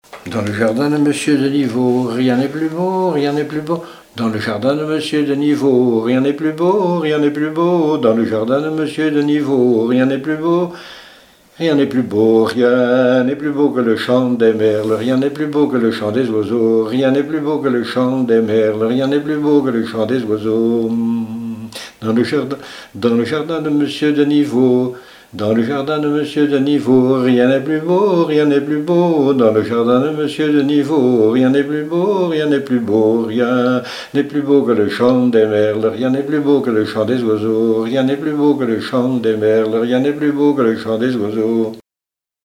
Chants brefs - A danser
branle : avant-deux
Répertoire de chants brefs pour la danse
Pièce musicale inédite